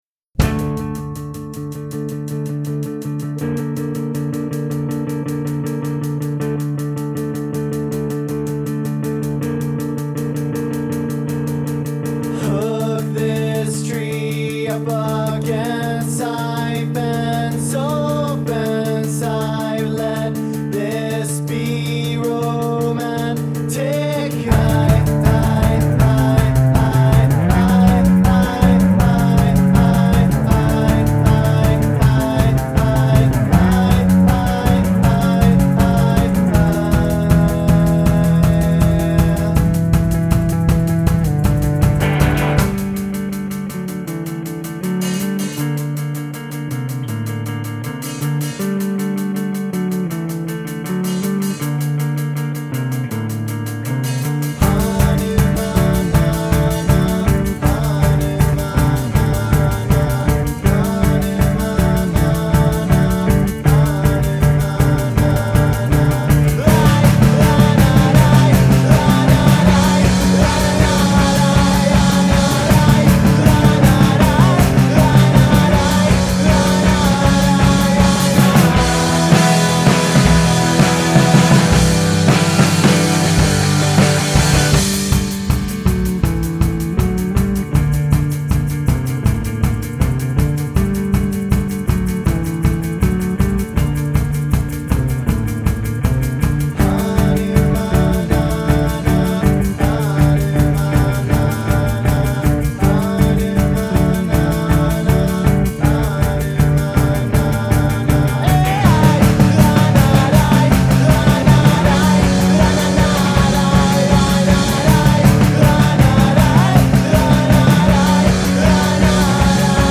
vocals, guitar
vocals, drums